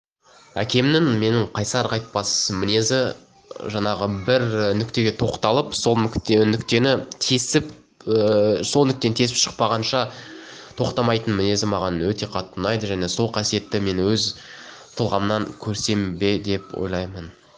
Шабытым оянғанда дауысымды актерлер сияқты мәнерге келтіріп, әкемнің өлеңдерін оқимын.